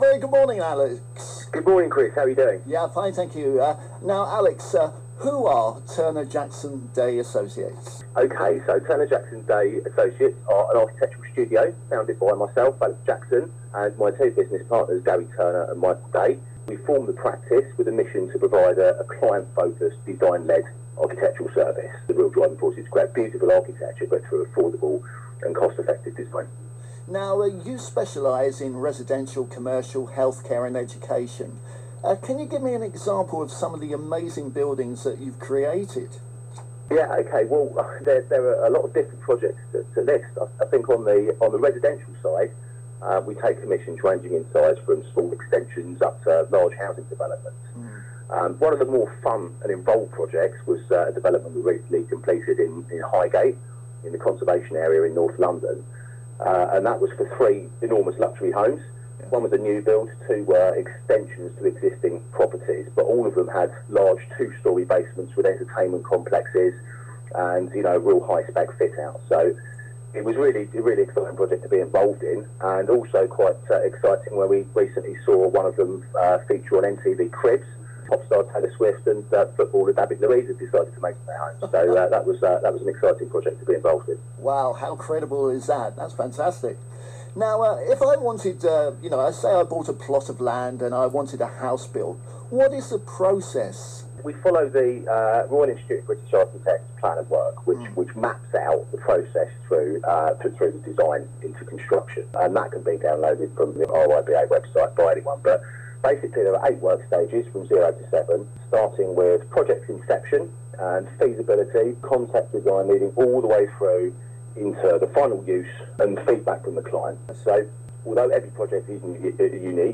Radio interview with Director